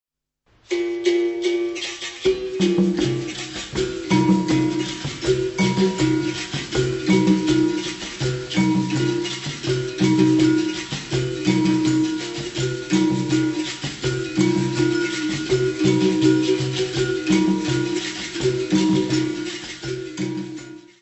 Carnavals du Bresil : Rio, Recife, Bahia : live recording = Carnivals of Brazil
Área:  Tradições Nacionais
Capoeira - Danse - Bahia.